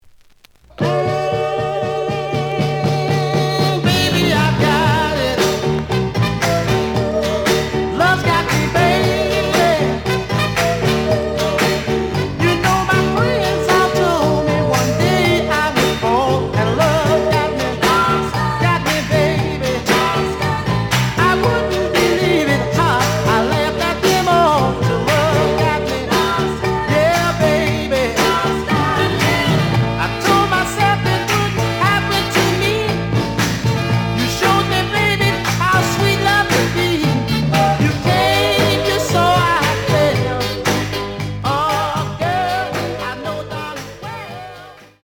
The audio sample is recorded from the actual item.
Slight edge warp.